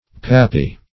Pappy \Pap"py\, a. [From Pap soft food.]